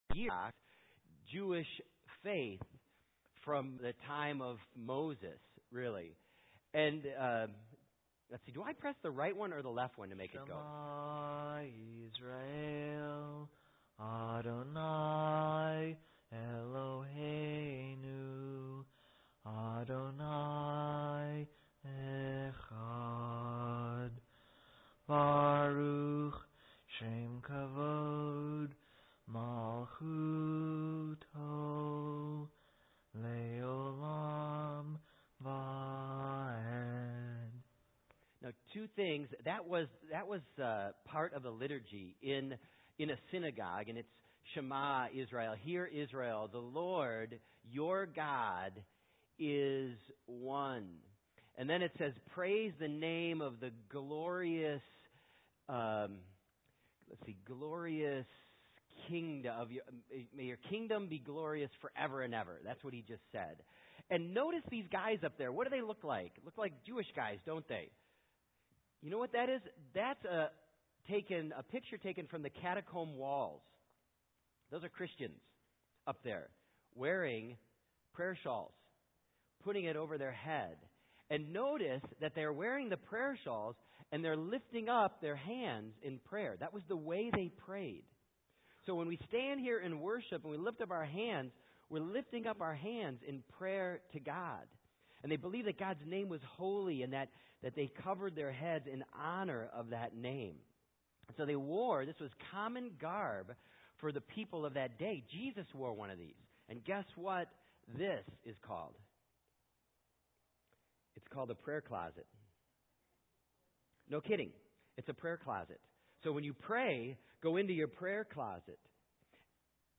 Find a previous sermon | Subscribe to COH's Sermon Podcast